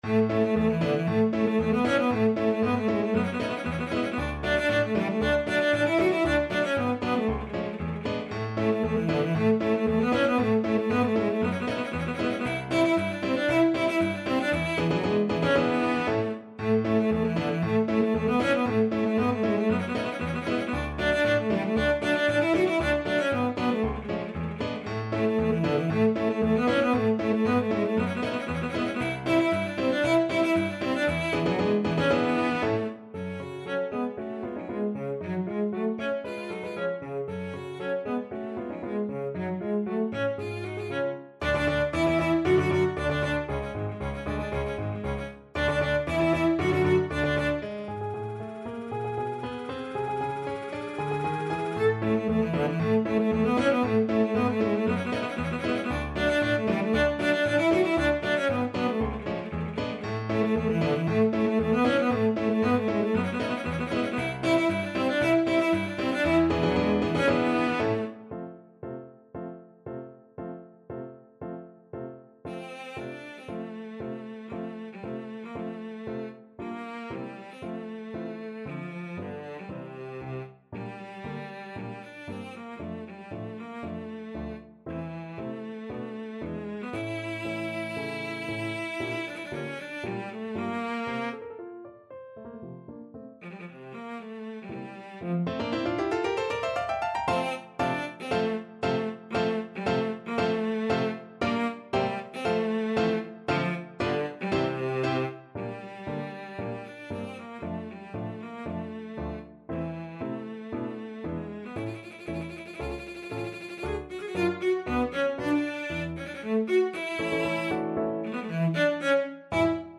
Classical Bizet, Georges Carmen Overture (Prelude) Cello version
Cello
2/4 (View more 2/4 Music)
A major (Sounding Pitch) (View more A major Music for Cello )
Allegro giocoso =116 (View more music marked Allegro giocoso)
Classical (View more Classical Cello Music)